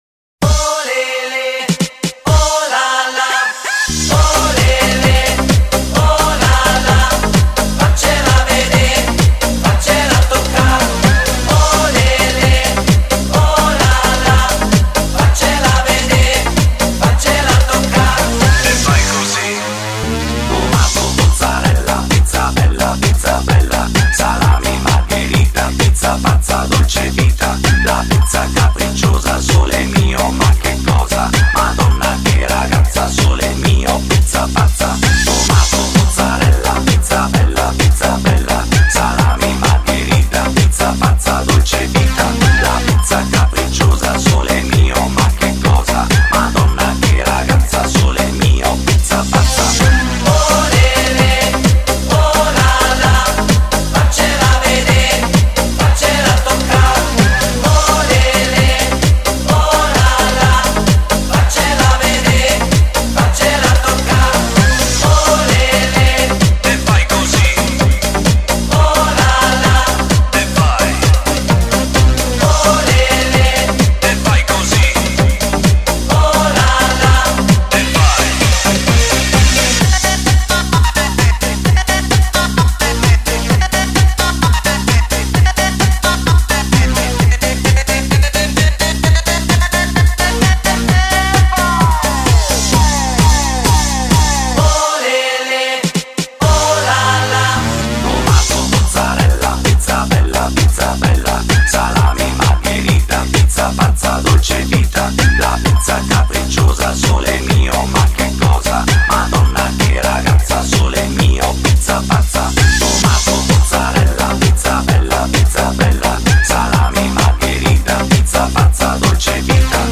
风格: 慢摇
一首经典之极的意大利慢摇舞曲，